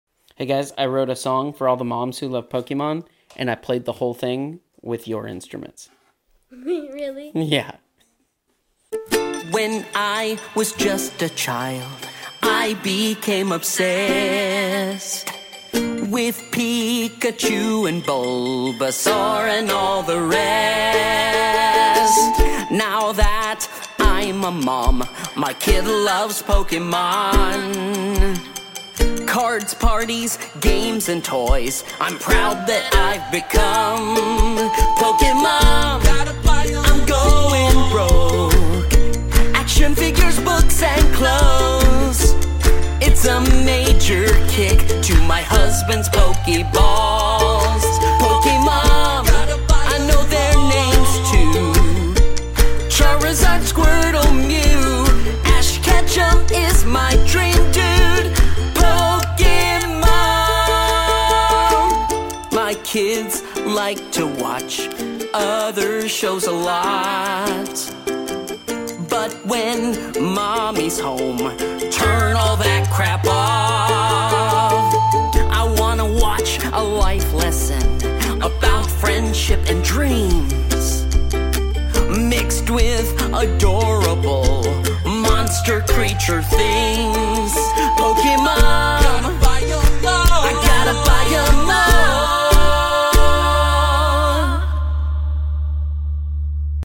played the whole thing on kids toys!